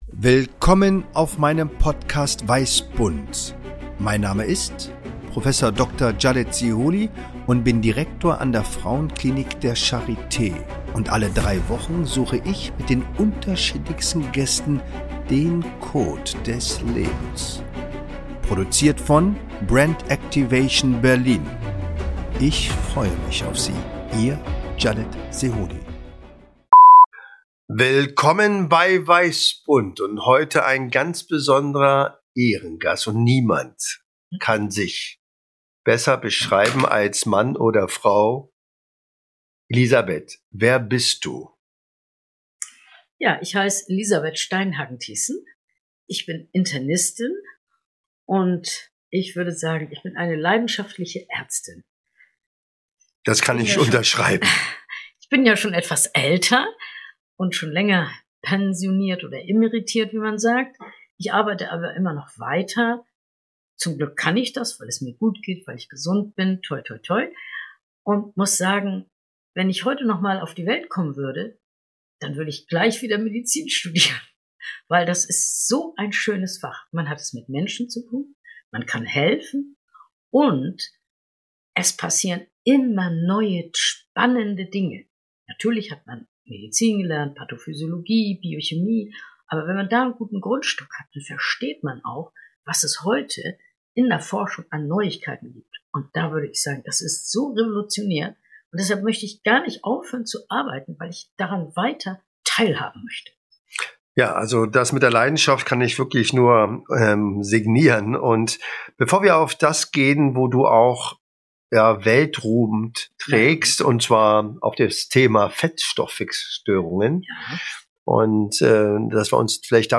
Spontan, intuitiv, ohne Skript, Improvisation pur!